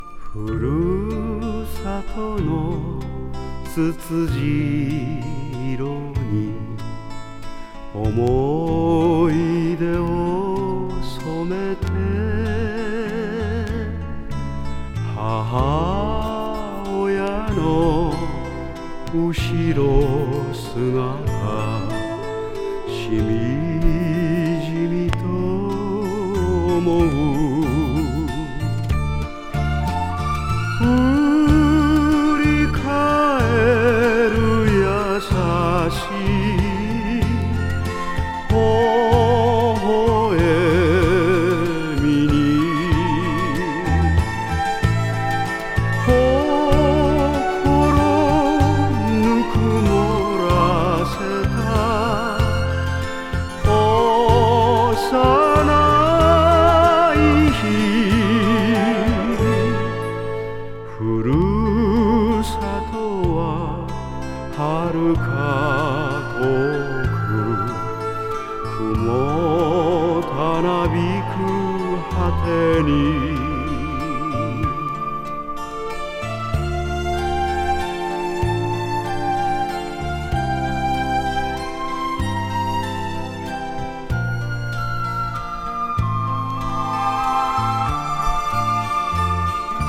和モノ / ポピュラー
(断続的に薄くチリノイズ入ります)